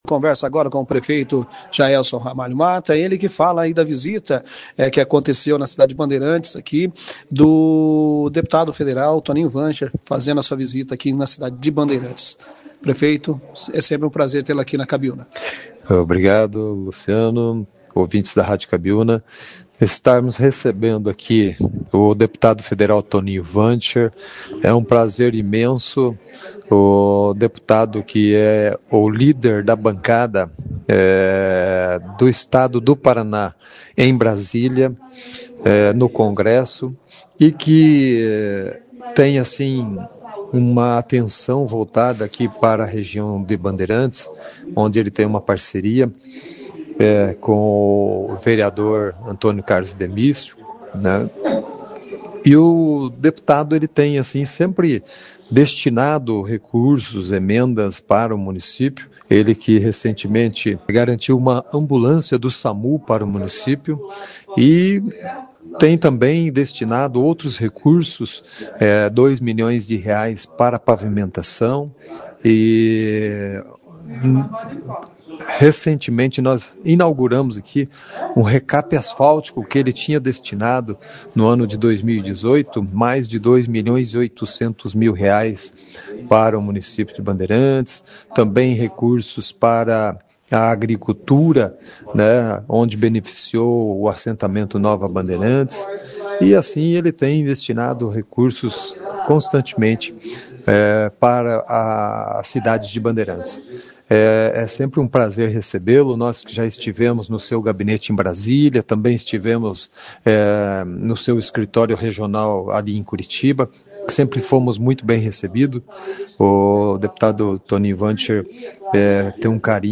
A passagem do deputado foi destaque na 1ª edição do jornal Operação Cidade desta quinta-feira, 24.